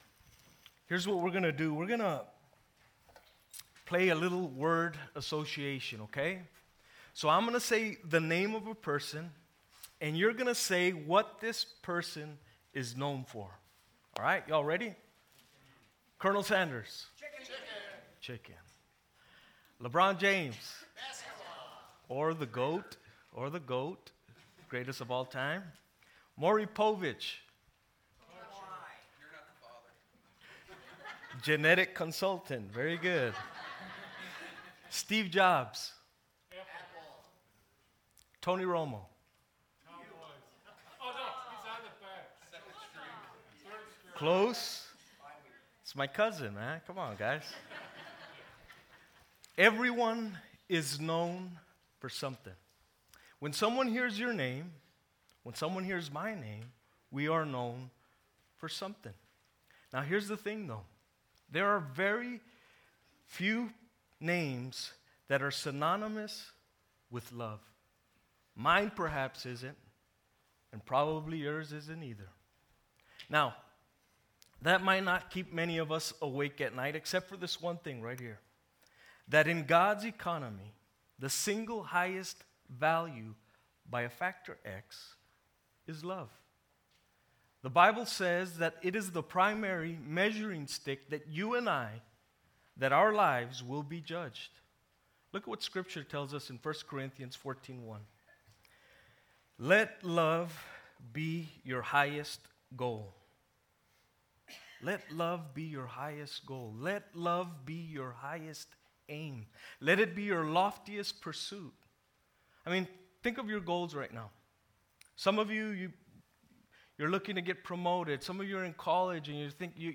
Messages - Christ Community Church